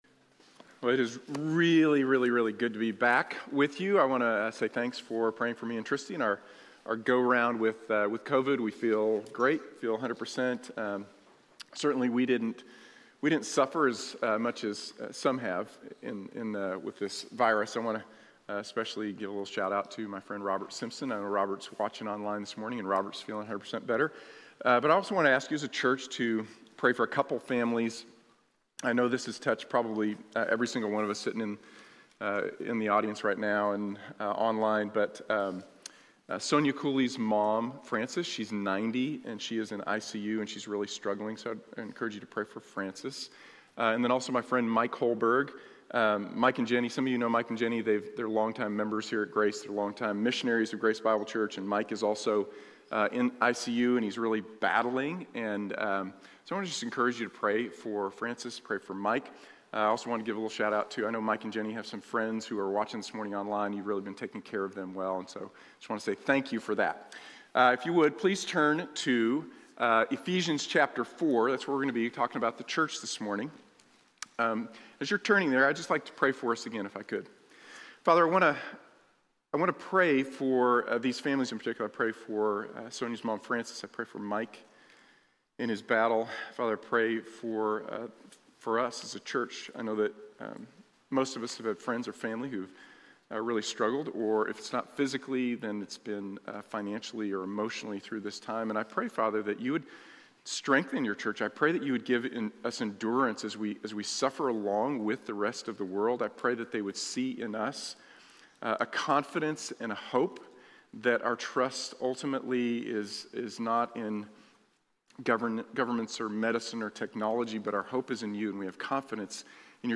¿Qué es la Iglesia? | Sermón de la Iglesia Bíblica de la Gracia